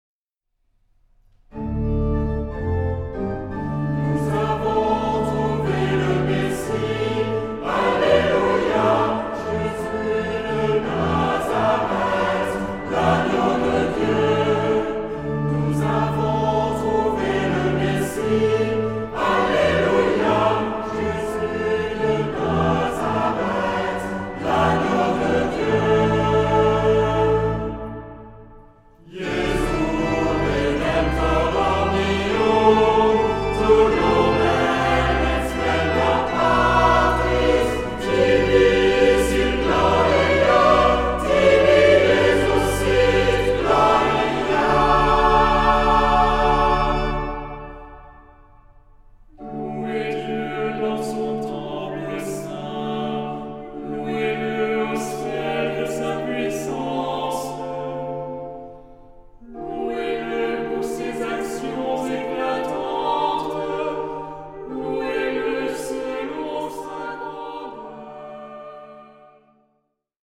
Genre-Style-Form: troparium ; Psalmody
Mood of the piece: joyous
Type of Choir: SATB  (4 mixed + congregation voices )
Instruments: Organ (1) ; Melody instrument (1)
Tonality: D major
Trompette